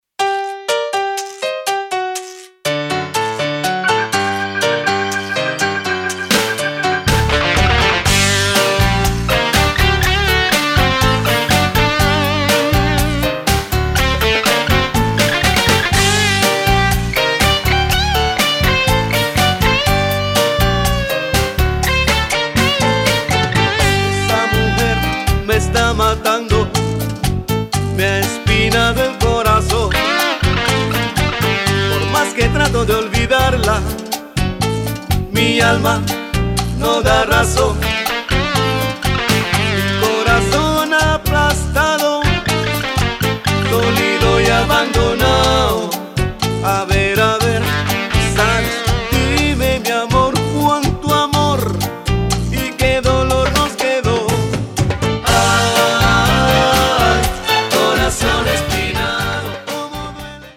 Sample tracks of this Exclusive  Mix CD: